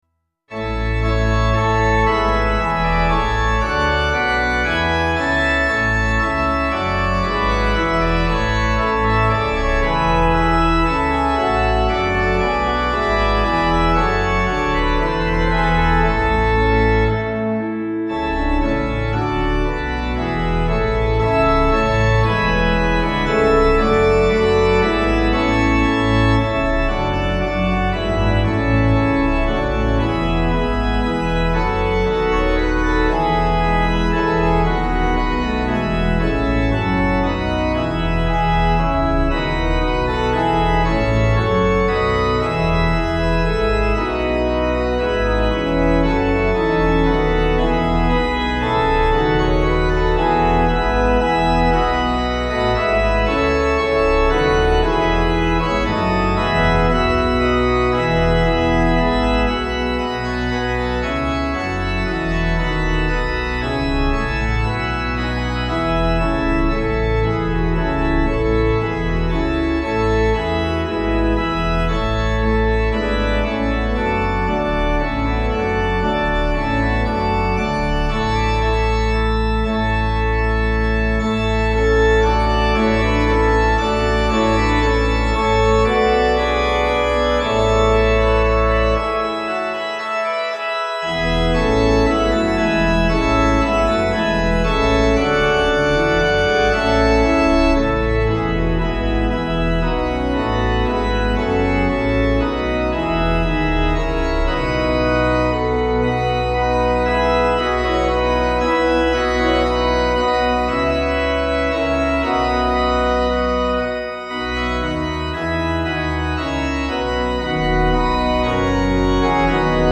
Easy Listening   A
Organ Duet